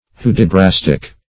Search Result for " hudibrastic" : The Collaborative International Dictionary of English v.0.48: Hudibrastic \Hu`di*bras"tic\, a. Similar to, or in the style of, the poem "Hudibras," by Samuel Butler; in the style of doggerel verse.
hudibrastic.mp3